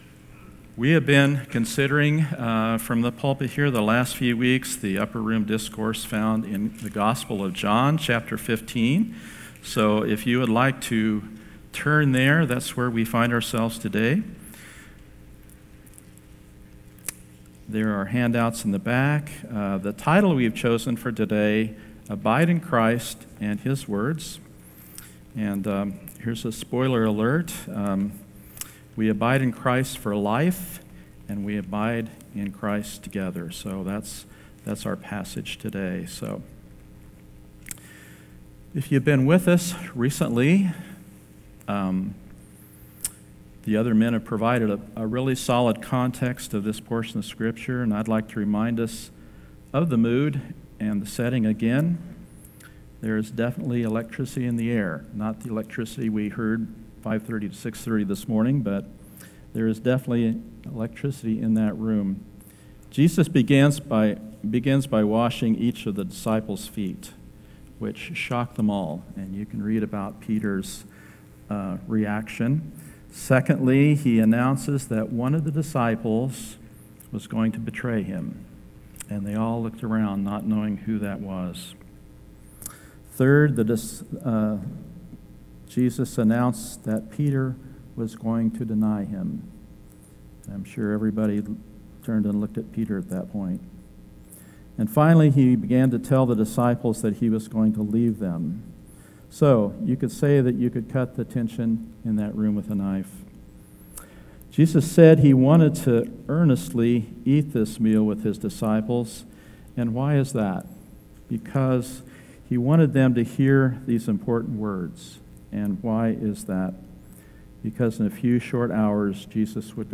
2025 One-ology John 15:4-7 In this sermon